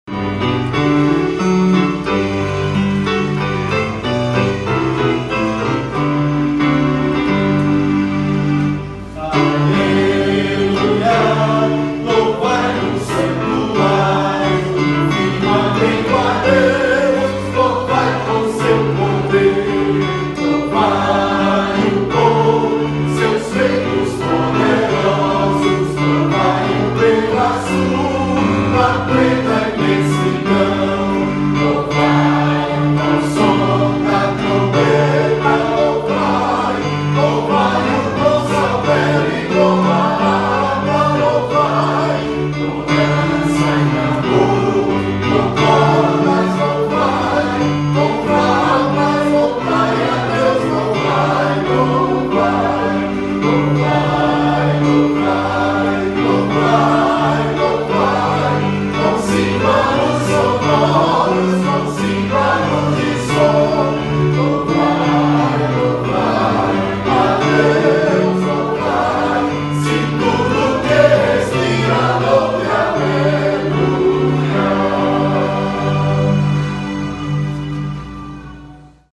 salmo_150B_cantado.mp3